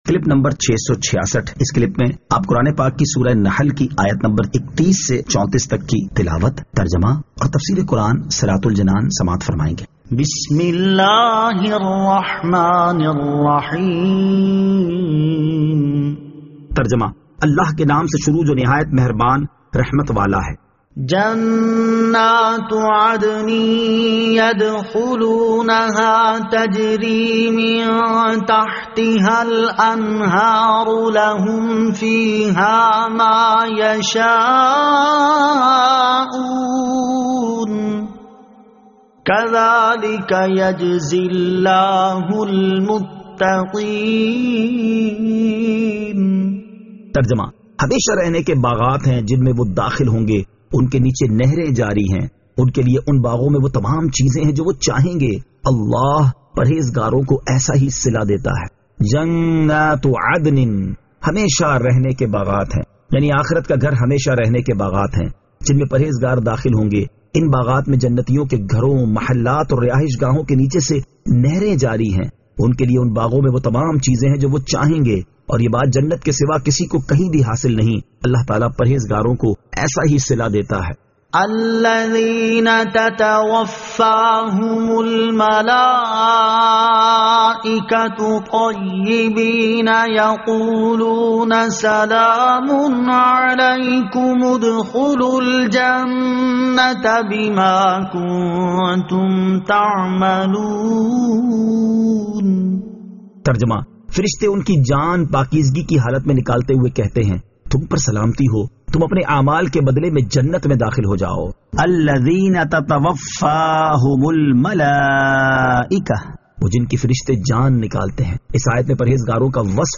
Surah An-Nahl Ayat 31 To 34 Tilawat , Tarjama , Tafseer